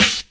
snare 02.wav